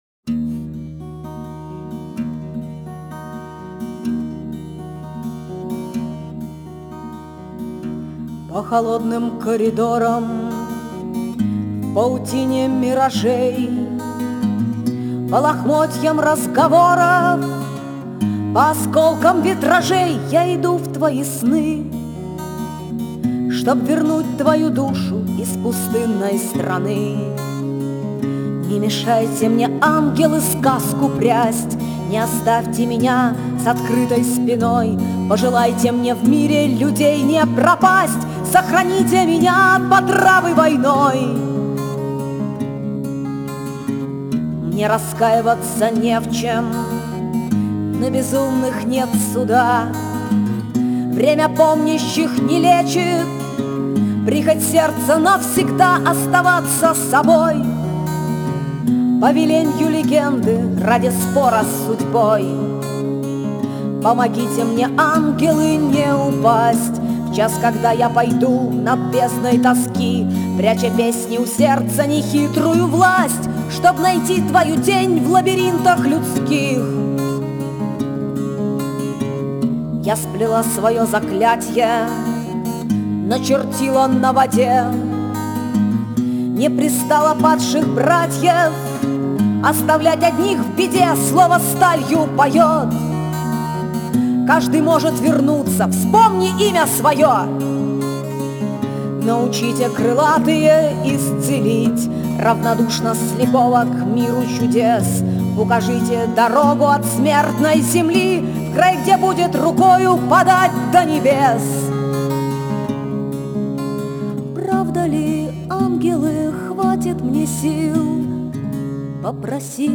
Стиль: Авторская песня, Фолк